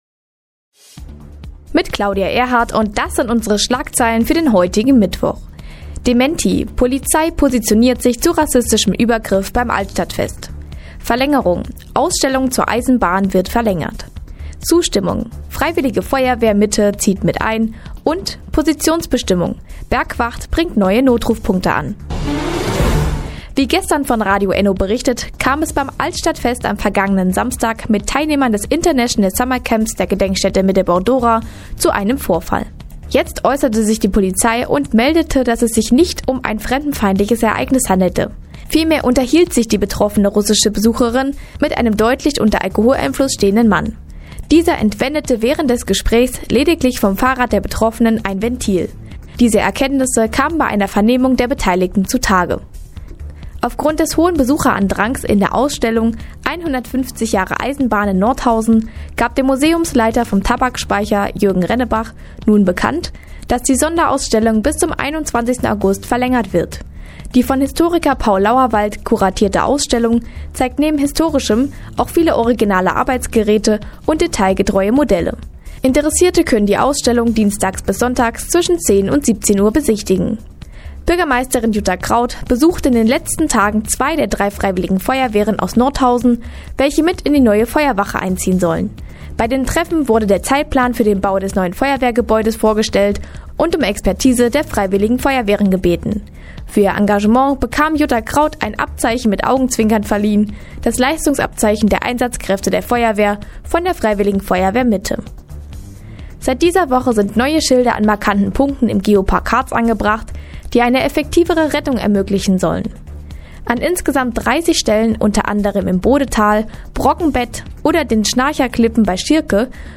Mi, 16:52 Uhr 10.08.2016 Neues von Radio ENNO „Fazit des Tages“ Seit Jahren kooperieren die Nordthüringer Online-Zeitungen und das Nordhäuser Bürgerradio ENNO. Die tägliche Nachrichtensendung ist jetzt hier zu hören.